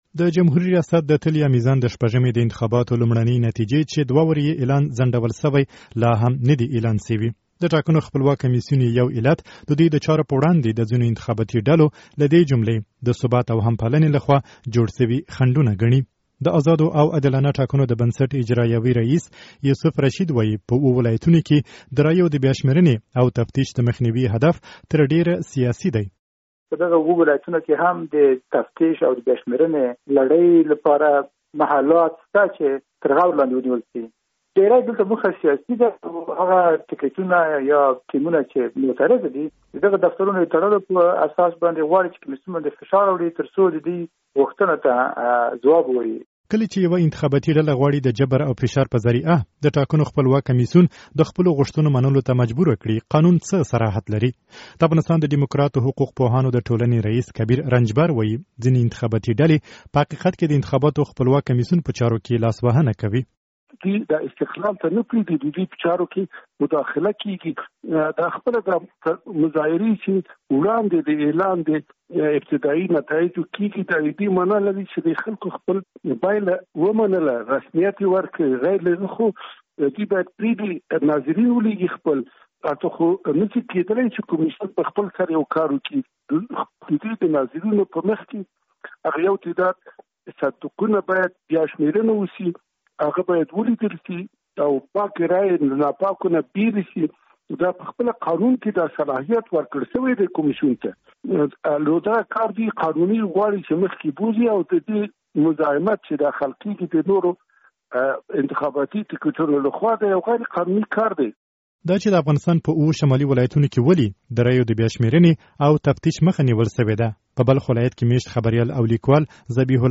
د ټاکنو پایلو په اړه راپور